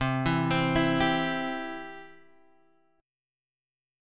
C Chord
Listen to C strummed